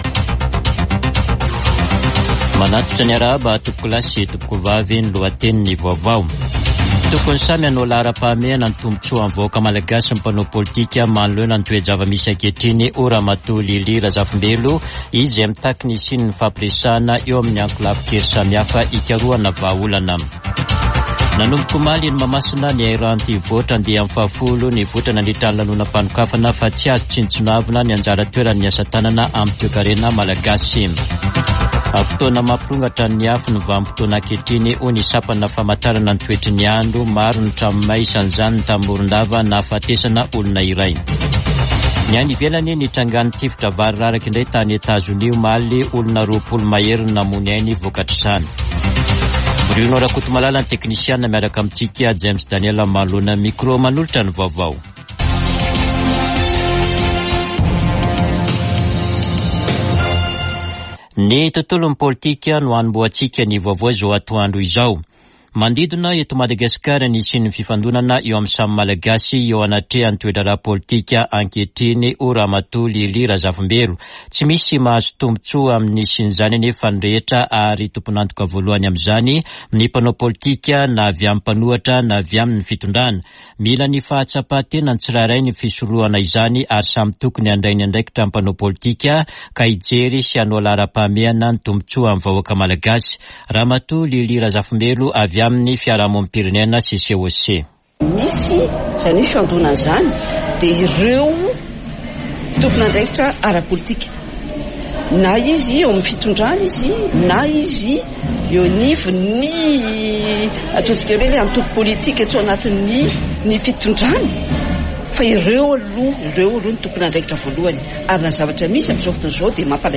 [Vaovao antoandro] Alakamisy 26 ôktôbra 2023